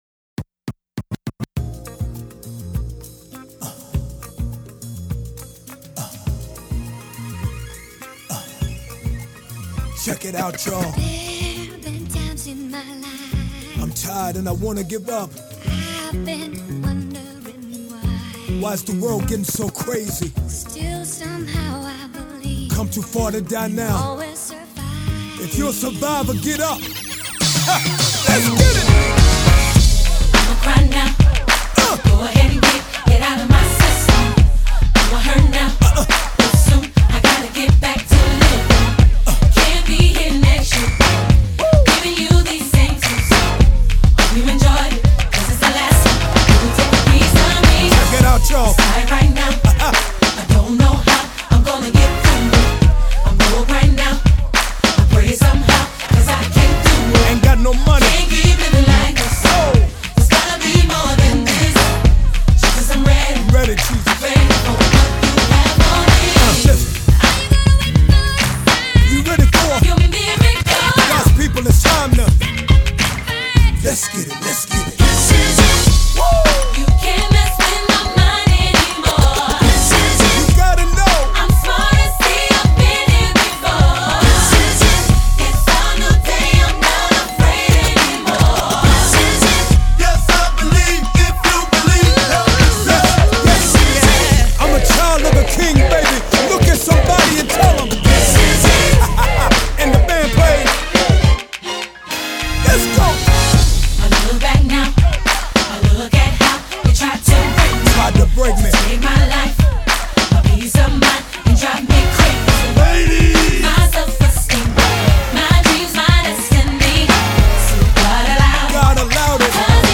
urban beats